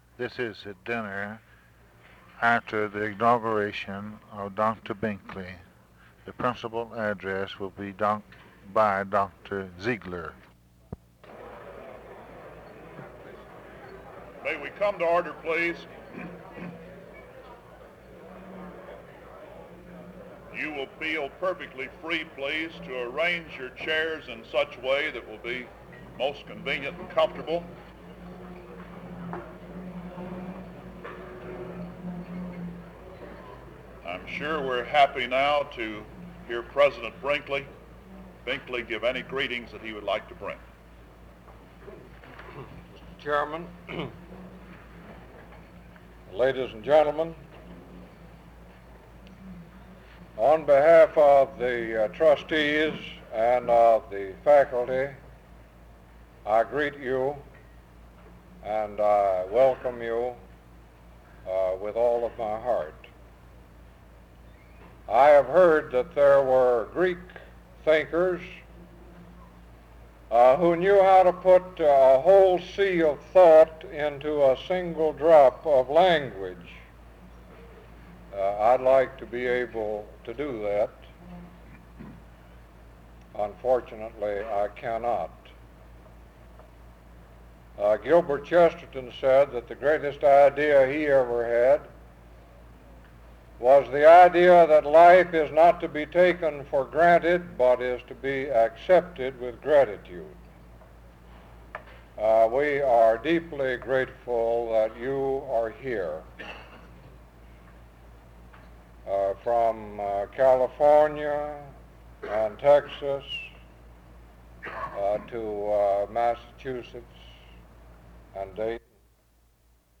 A recording of the dinner
gives the address from 25:09-1:02:56.
gives the benediction from 1:03:09-1:05:03.